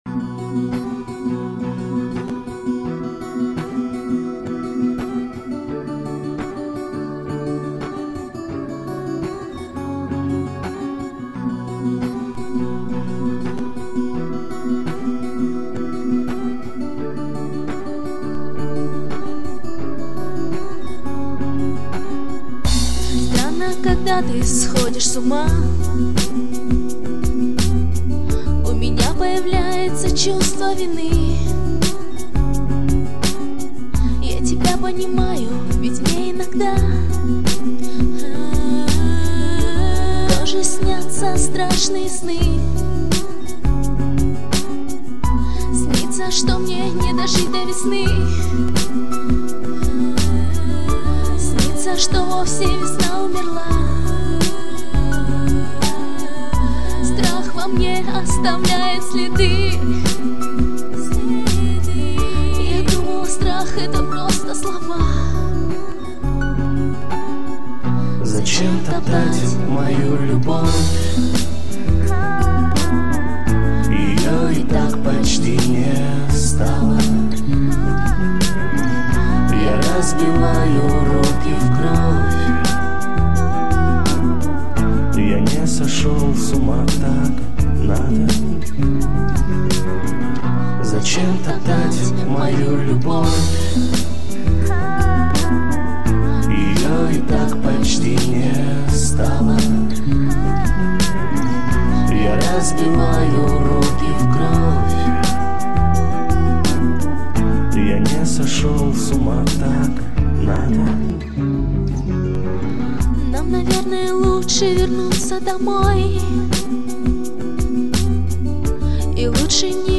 полуакустическая версия